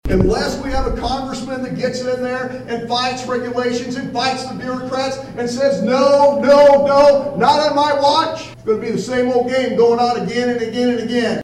Republican House & Senate candidates speak in Atlantic Monday evening
(Atlantic, Iowa) – Cass County Republicans hosted a “Know Your Candidates” forum Monday evening, at the Cass County Community Center in Atlantic.